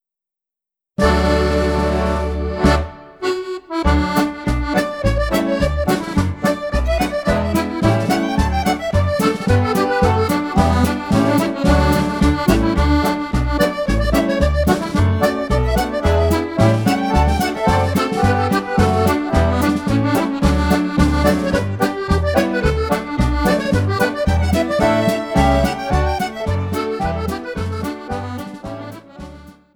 Reel